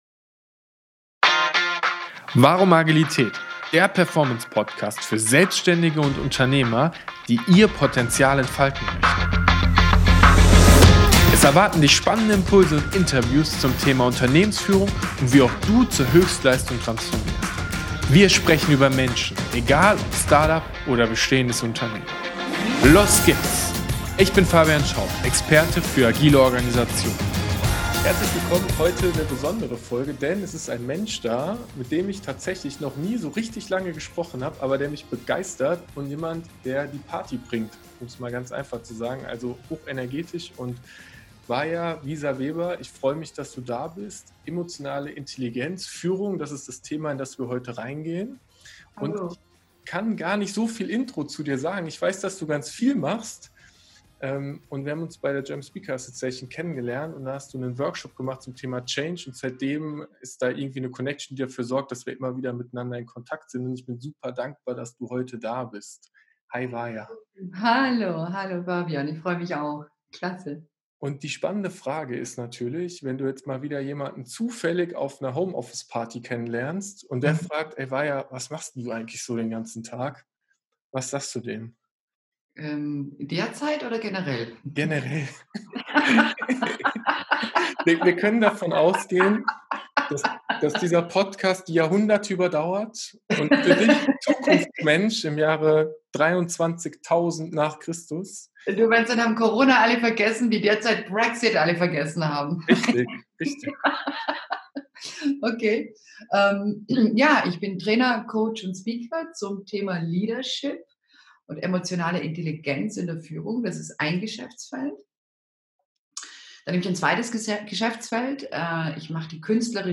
#029 Führen mit emotionaler Intelligenz - Interview